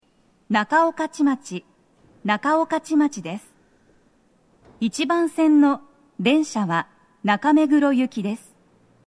‘«Œ³’ˆÓŠ«‹N•ú‘—‚Ì•t‘Ñ‚Í–³‚Aƒtƒ‹‚Ì“ïˆÕ“x‚Í•’Ê‚Å‚·B2”Ôü‚Ì•û‚ªAƒtƒ‹–Â‚è‚â‚·‚¢‚Å‚·B